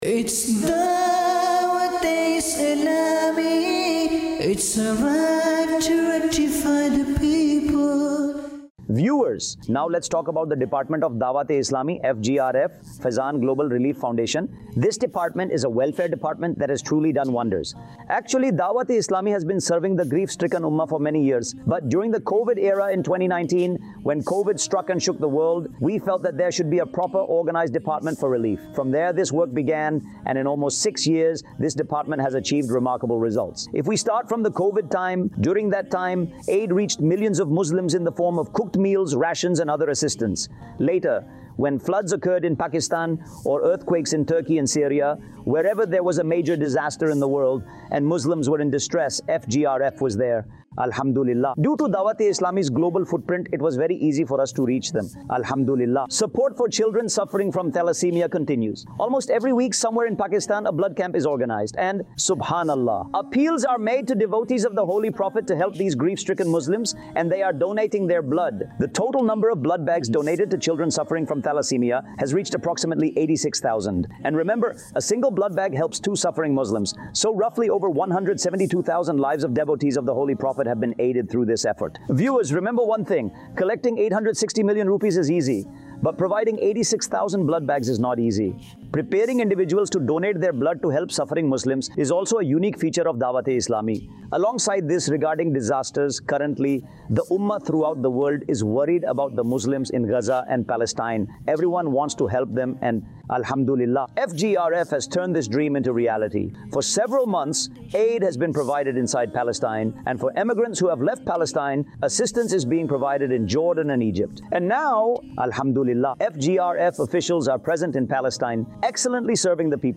khutba
FGRF | Department of Dawateislami | Documentary 2026 | AI Generated Audio